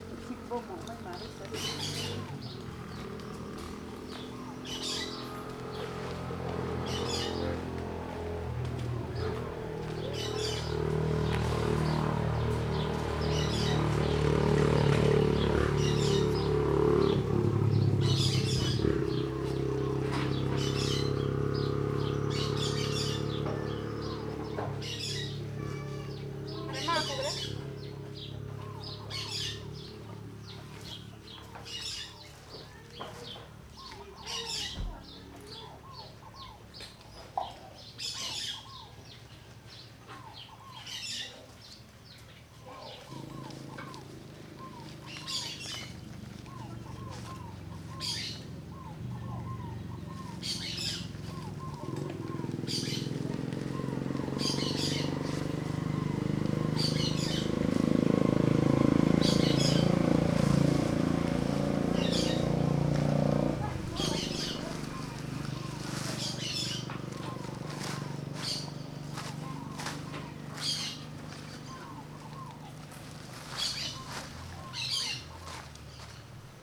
CSC-18-110-OL- Motos passando em estrada proxima de aldeia.wav